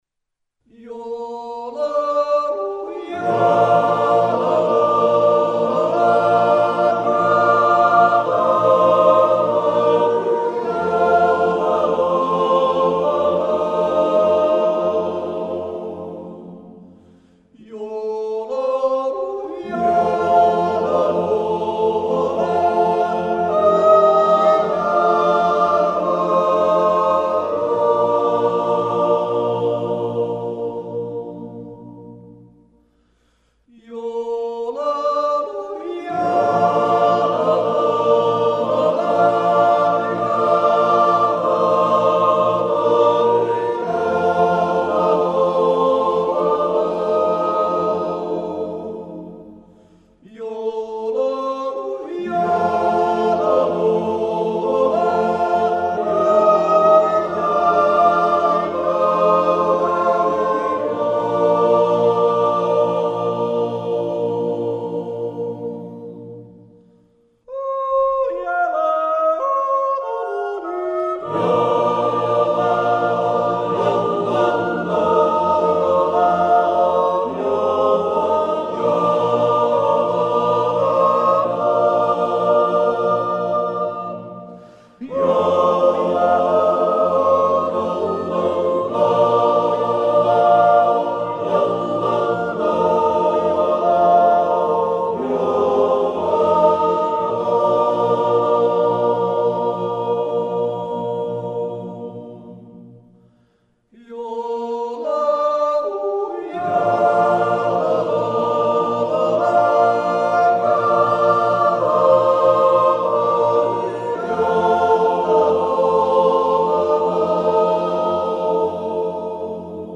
Natural yodel.